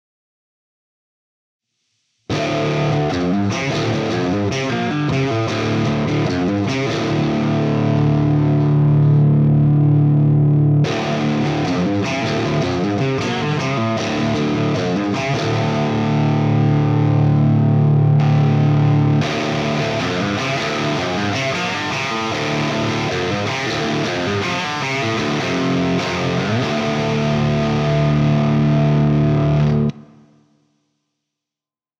ACTIVE-PURE-J-FET-PASSIVE.mp3